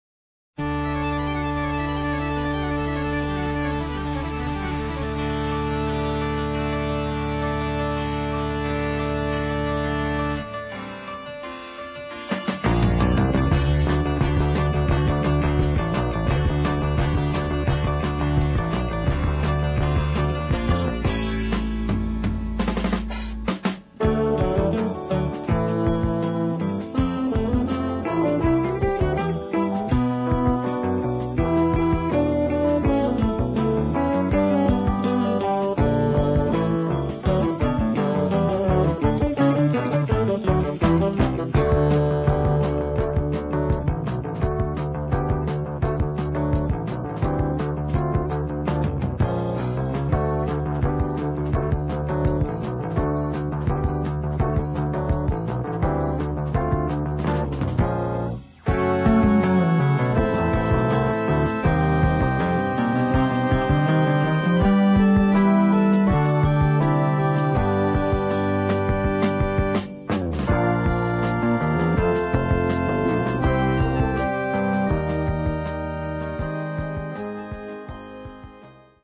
Stereo, 1:18, 64 Khz, (file size: 618 Kb).